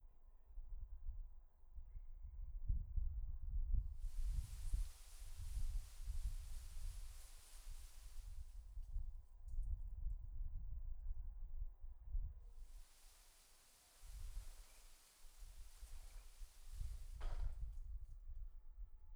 El sonido del viento agitando las ramas de un árbol.